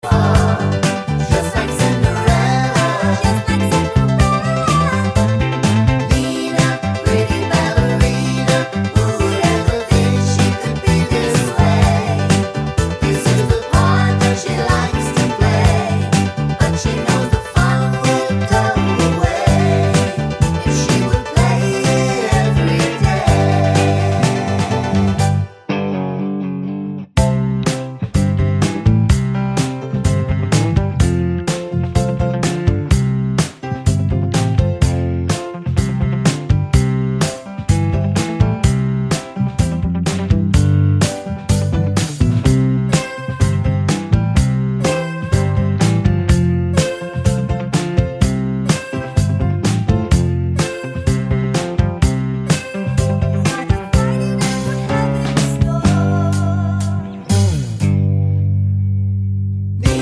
Clean Intro Version with No SFX Pop (1970s) 3:00 Buy £1.50